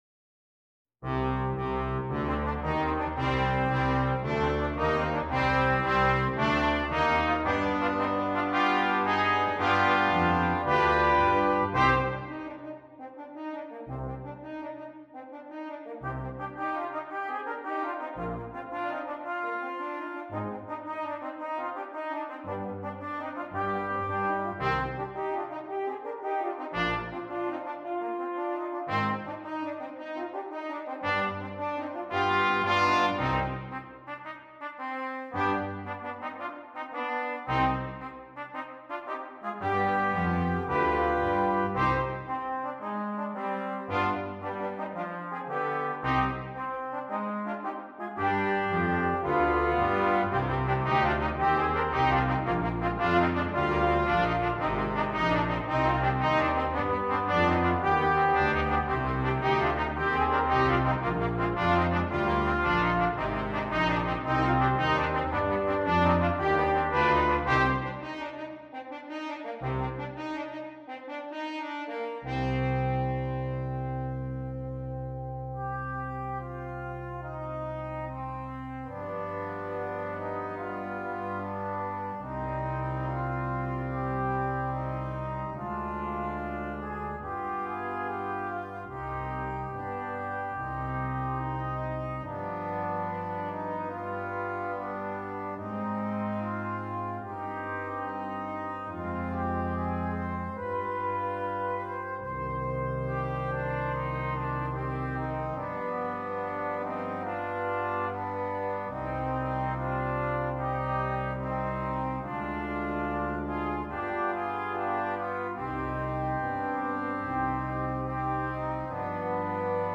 Brass Quintet
This is fun piratey piece for all.